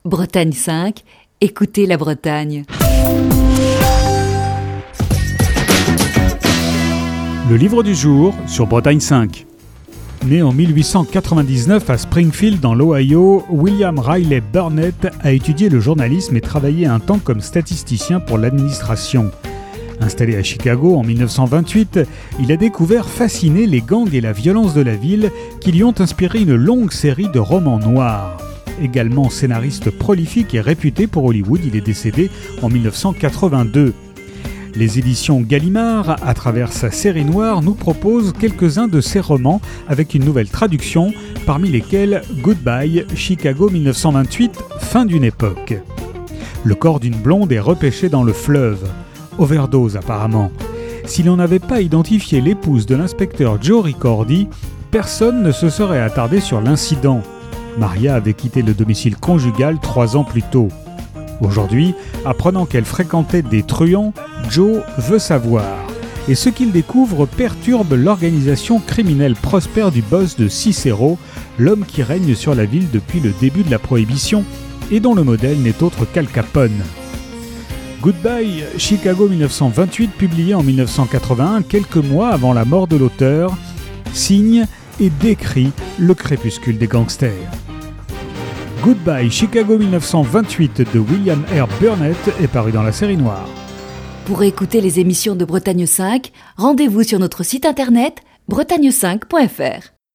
Chronique du 7 janvier 2021.